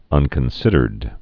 (ŭnkən-sĭdərd)